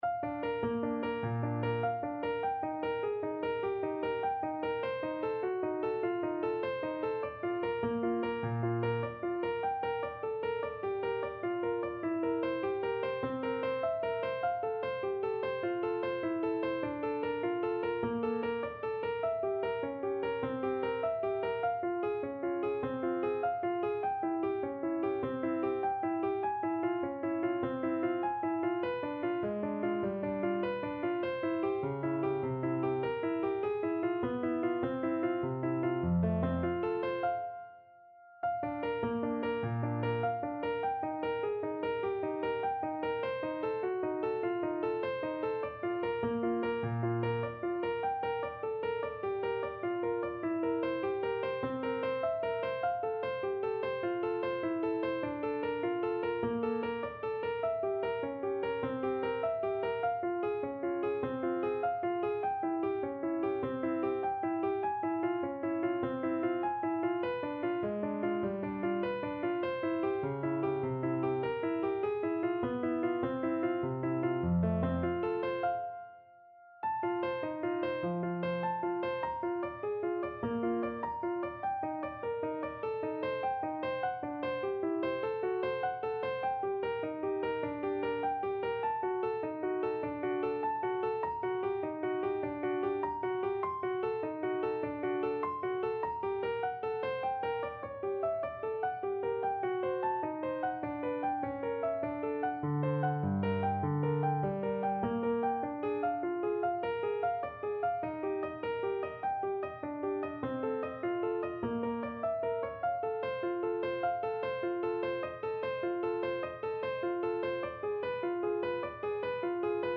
4/4 (View more 4/4 Music)
Bb major (Sounding Pitch) (View more Bb major Music for Piano )
Piano  (View more Intermediate Piano Music)
Classical (View more Classical Piano Music)
18th century    baroque